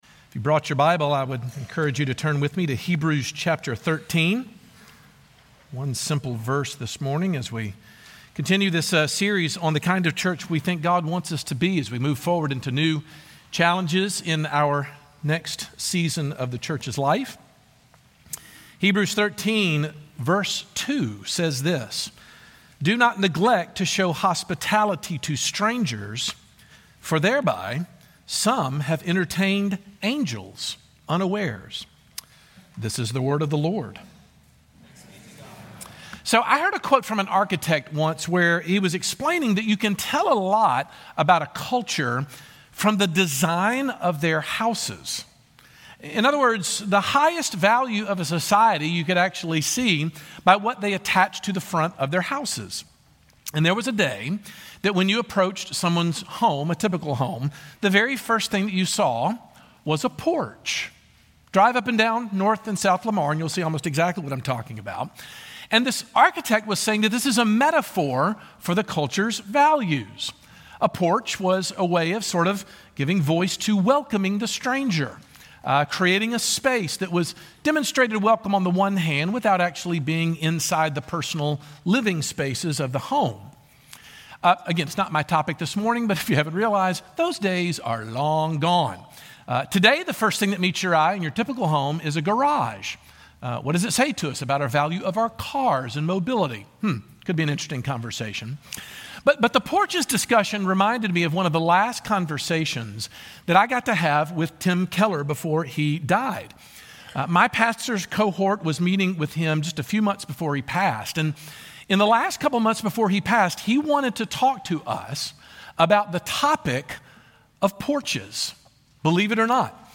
And how can we find ways to serve and love our communities without having to power through guilt as the only motivation to do so? Sermon